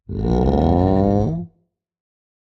1.21.5 / assets / minecraft / sounds / mob / sniffer / idle4.ogg